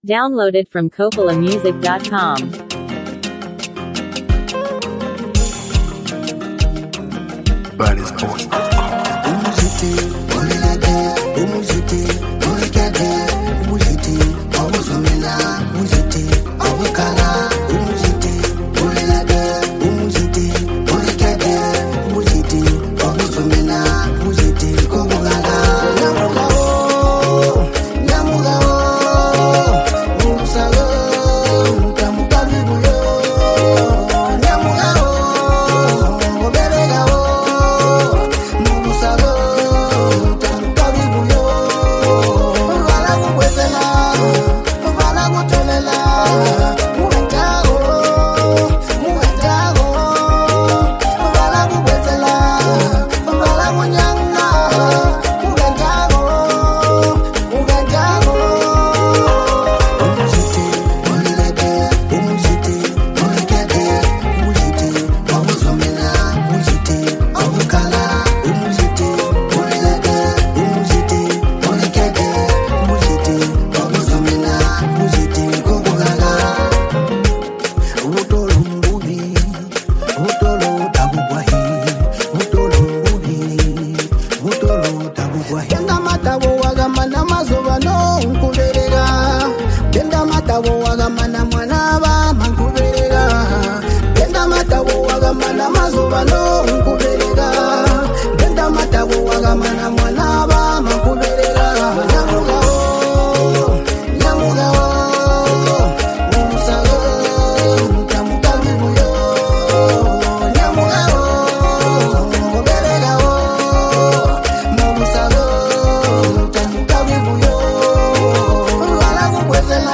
energetic voice and joyful spirit
With its vibrant melody and strong message